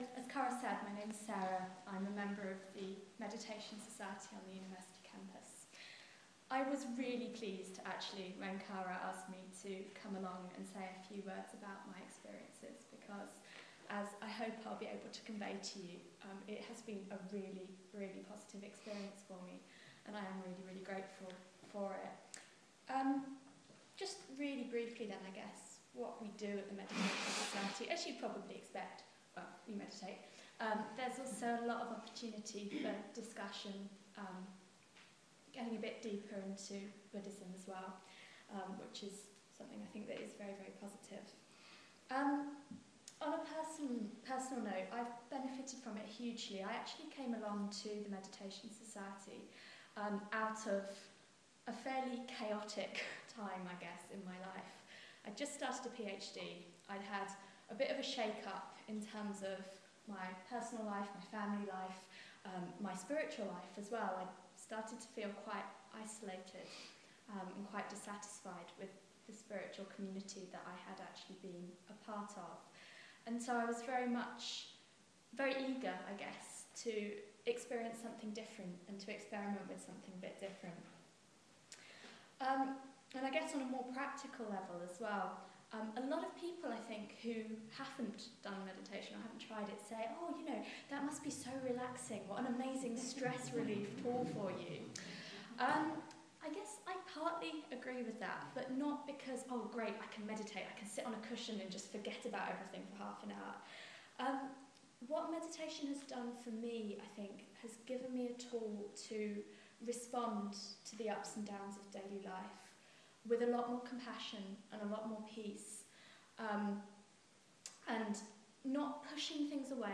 Part of celebrations in Birmingham, UK, to mark the anniversary of the Triratna Buddhist Community around the world.